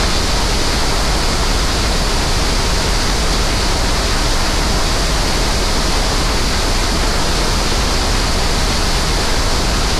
Waterfall1.ogg